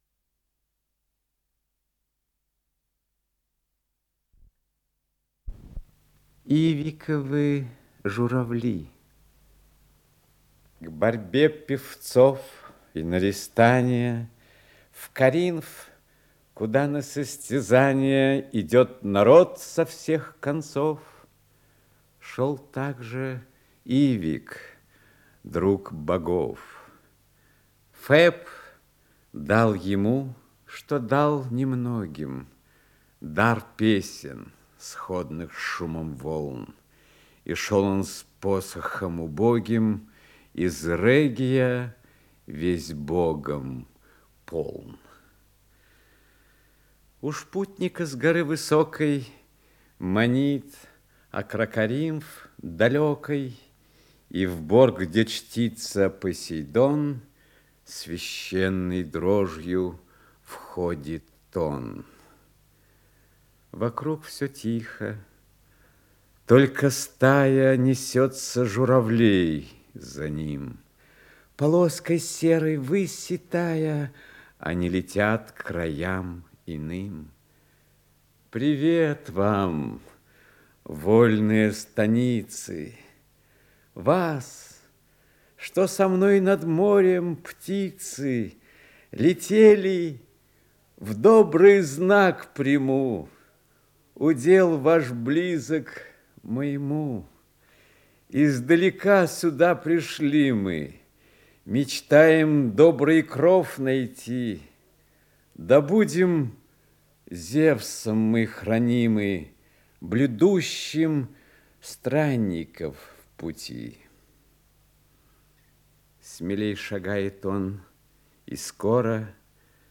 Исполнитель: Иннокентий Смоктуновский - чтение